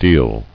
[deil]